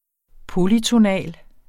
Udtale [ ˈpolytoˌnæl ]